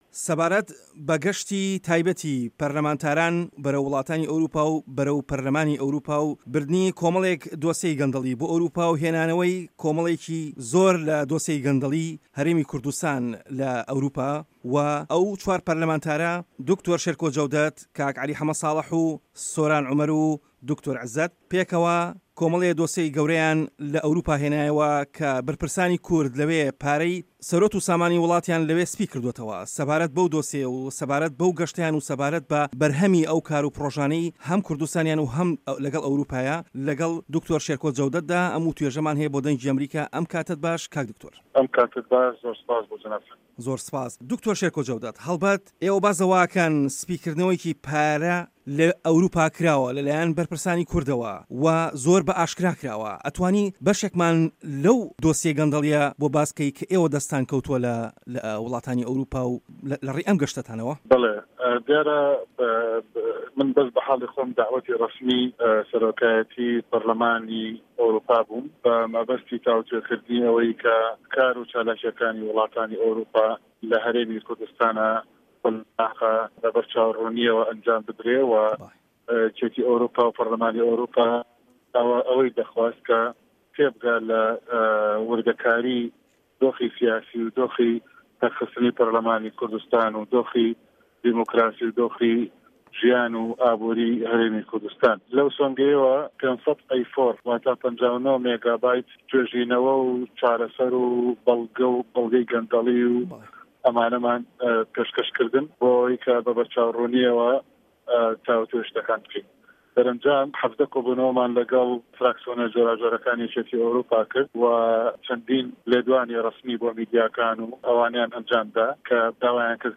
وتووێژی به‌ڕێز شێرکۆ جه‌وده‌ت